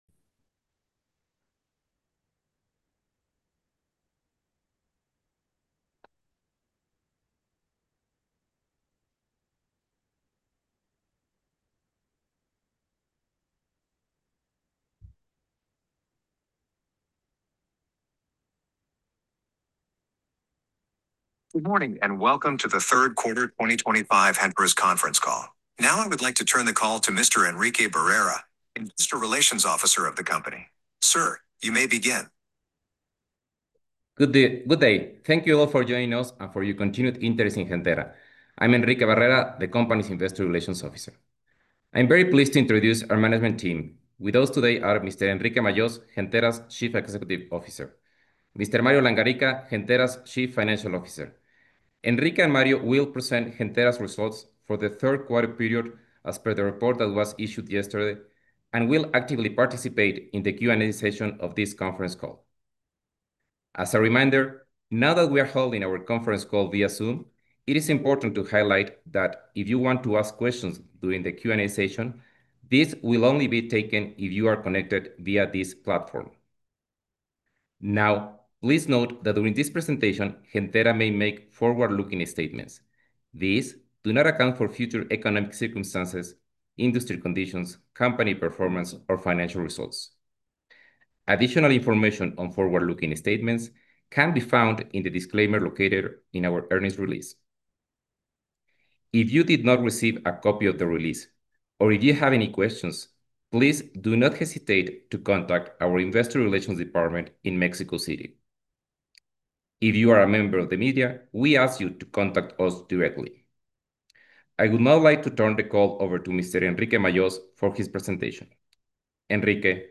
Reporte trimestral y conferencia de resultados